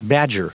Prononciation du mot badger en anglais (fichier audio)
Prononciation du mot : badger